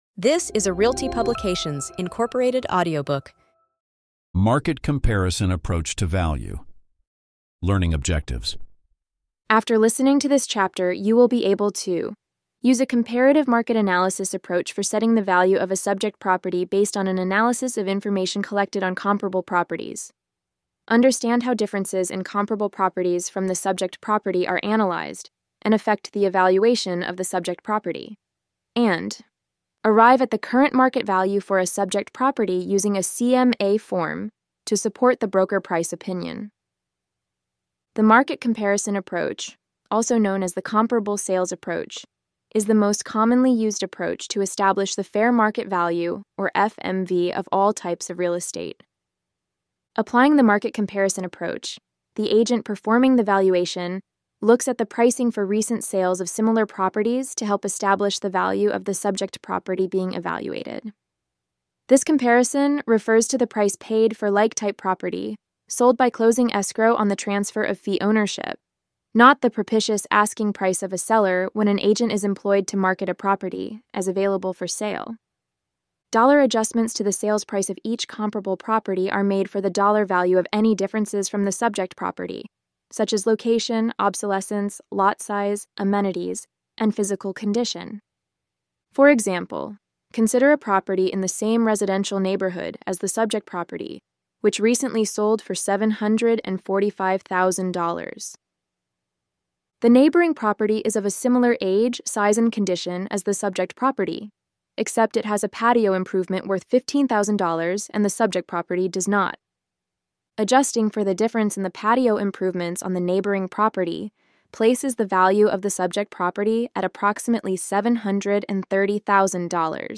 Follow along with an audio reading of this article adapted as a chapter from our upcoming Broker price opinion (BPO) course.